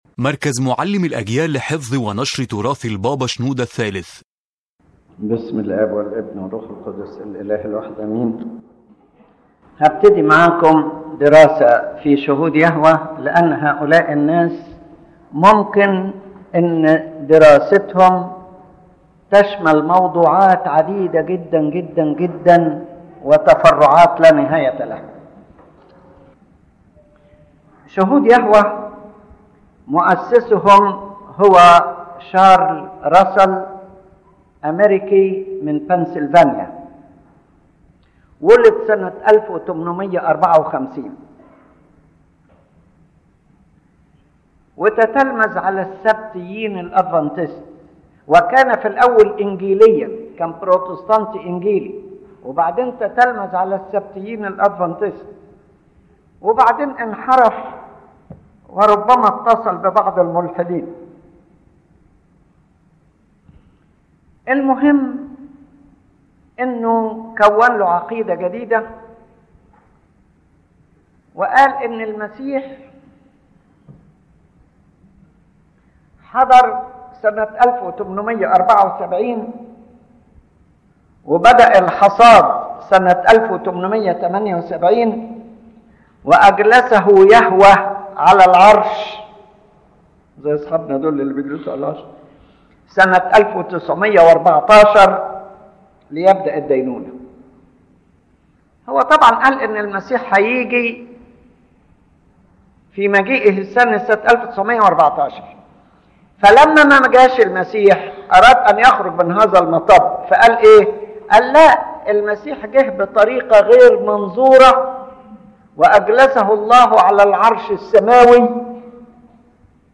The lecture presents an analytical overview of the doctrine of Jehovah’s Witnesses in terms of origin, history, and teachings, clarifying their doctrinal deviations compared to the Coptic Orthodox faith, especially regarding the person of Christ, salvation, and eternal life.